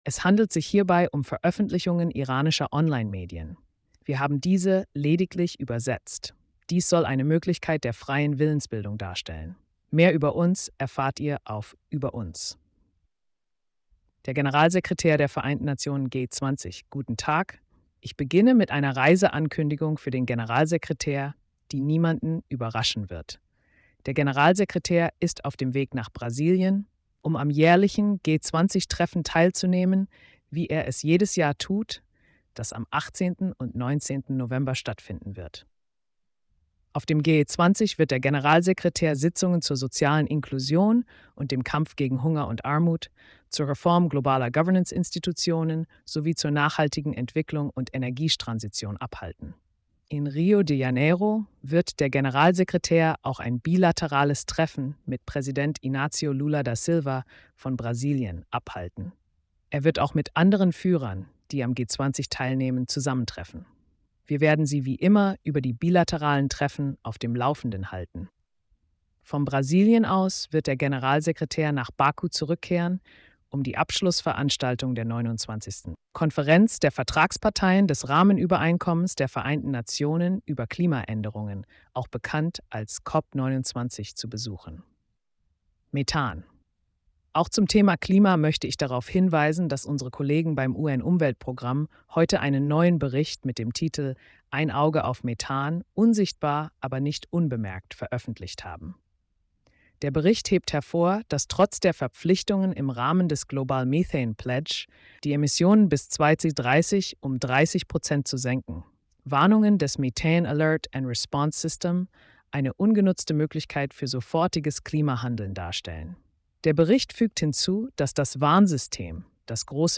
Spannende Themen: Libanon/Israel, Sudan, Ukraine & mehr - Tägliche Pressekonferenz (15. Nov 2024) | Vereinte Nationen